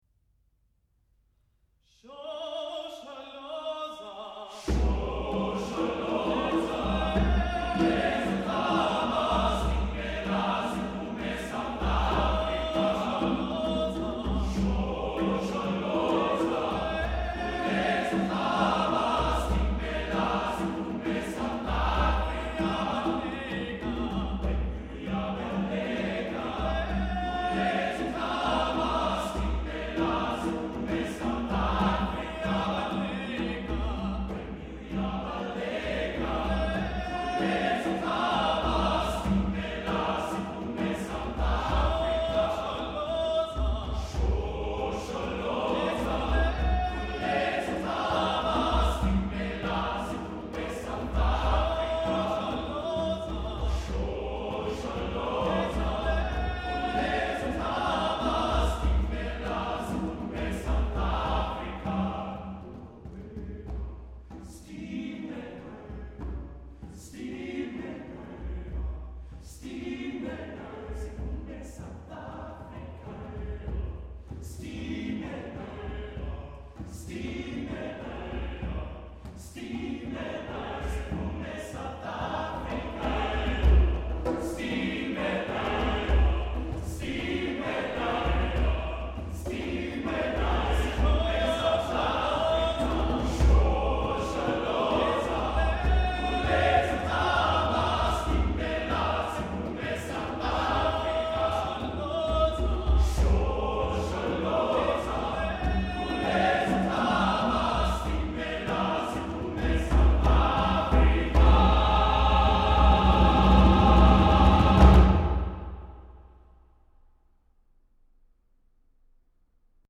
Accompaniment:      With Piano
Music Category:      Choral